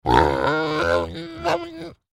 На этой странице собраны натуральные звуки диких кабанов: от хрюканья и рычания до топота копыт по лесу.
Недовольный визг кабана